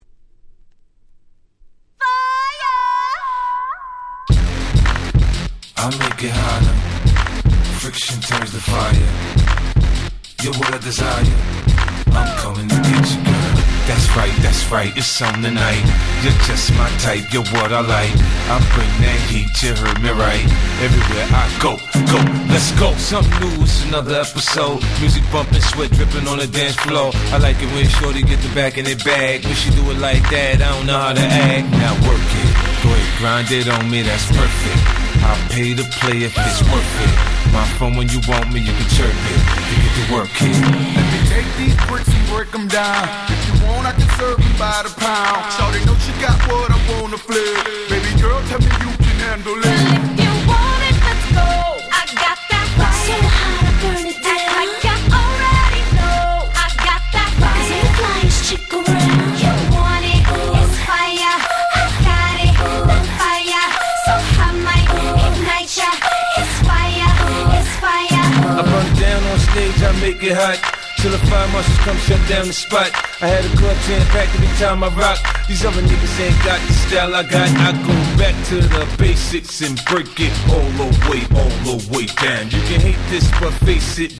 07' Smash Hit Hip Hop !!